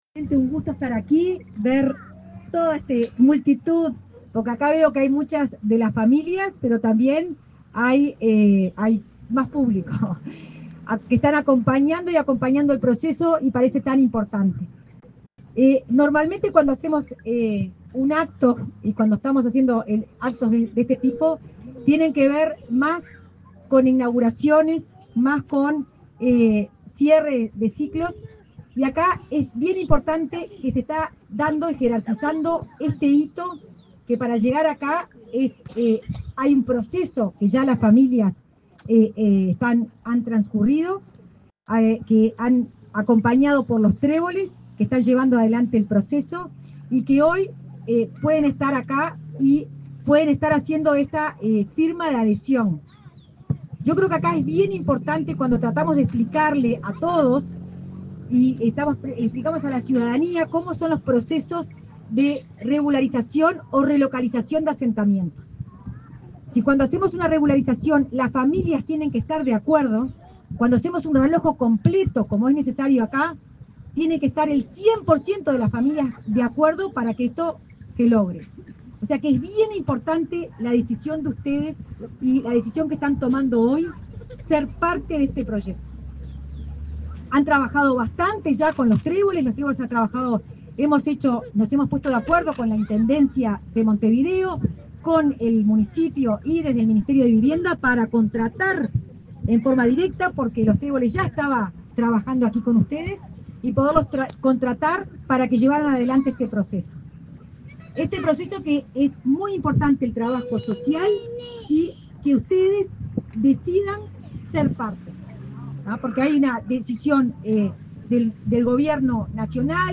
Palabras de la directora de Integración Social y Urbana de Vivienda, Florencia Arbeleche
Palabras de la directora de Integración Social y Urbana de Vivienda, Florencia Arbeleche 19/12/2024 Compartir Facebook X Copiar enlace WhatsApp LinkedIn Este 19 de diciembre se realizó la firma de consentimiento de realojo para familias del barrio Felipe Cardoso, Montevideo. En el evento, la directora de Integración Social y Urbana de Vivienda, Florencia Arbeleche, realizó declaraciones.